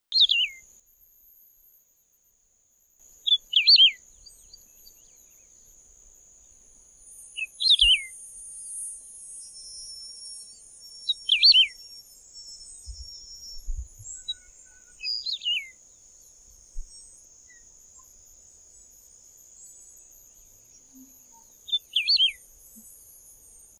Emberizoides herbicola - Coludo grande
Emberizoides herbicola - Coludo grande.wav